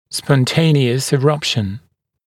[spɔn’teɪnɪəs ɪ’rʌpʃn][спон’тэйниэс и’рапшн]самопроизвольное прорезывание, спонтанное прорезывание